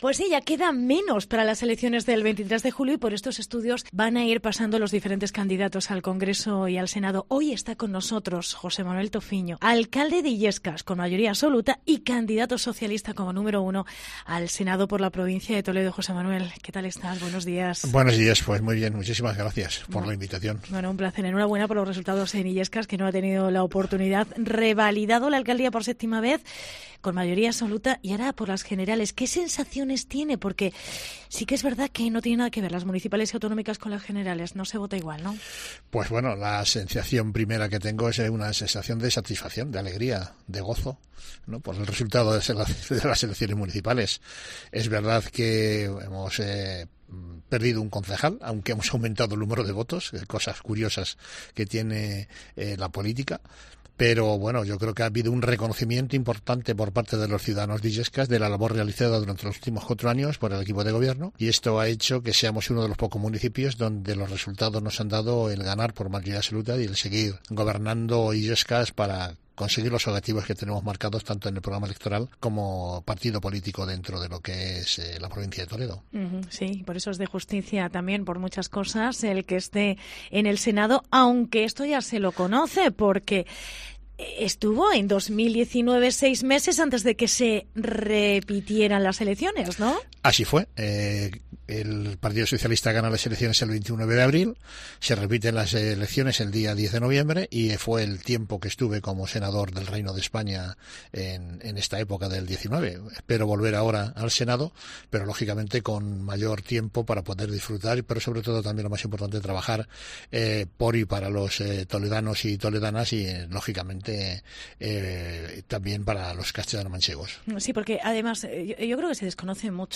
ENTREVISTA
Escucha aquí la entrevista completa a José Manuel Tofiño, alcalde de Illescas con mayoría absoluta y candidato socialista como número uno al Senado por la provincia de Toledo